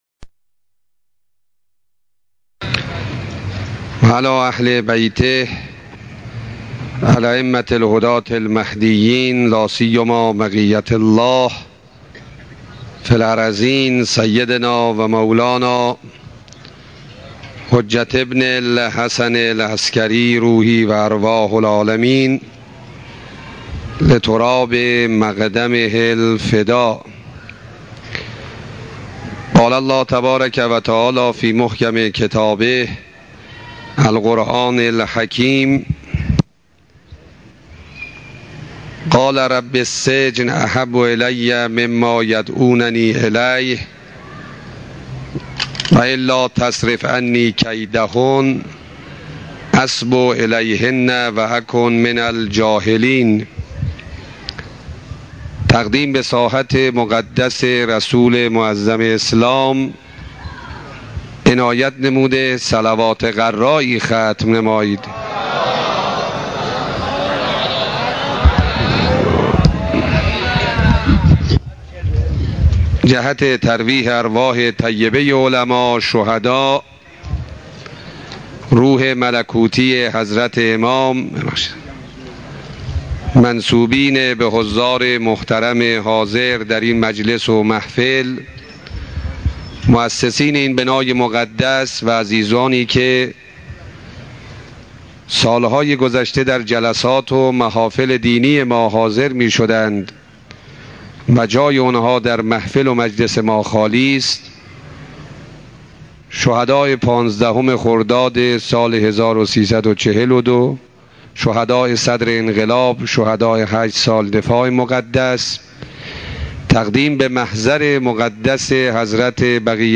سخرانی